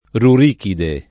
rurikide → rjurikide